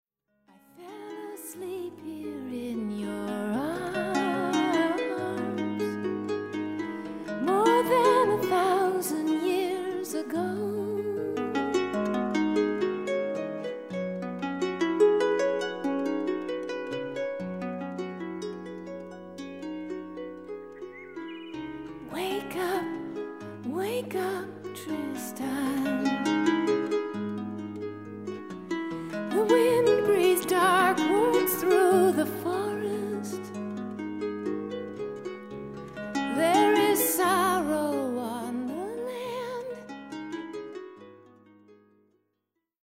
folk harp
Recorded & mixed at Powerplay Studios, Maur – Switzerland